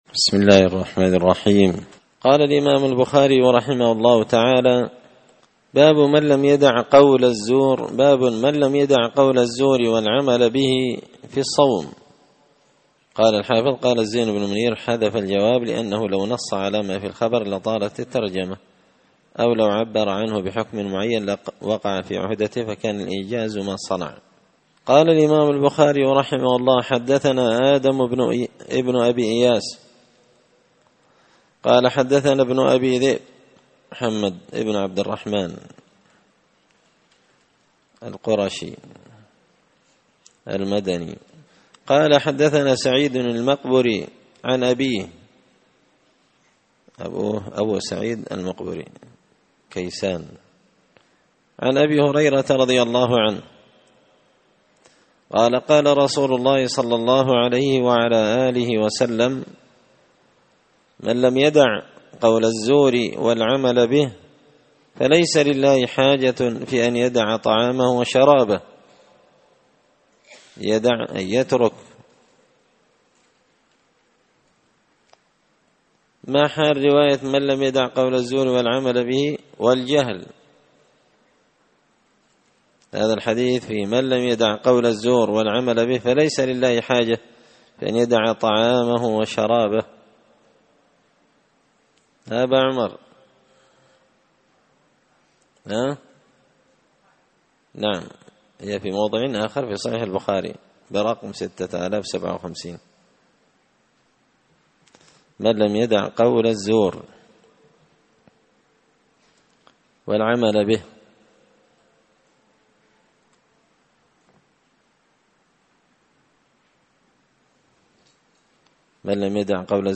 الأثنين 28 شعبان 1444 هــــ | 2- كتاب الصيام، الدروس، شرح صحيح البخاري | شارك بتعليقك | 12 المشاهدات
مسجد الفرقان قشن_المهرة_اليمن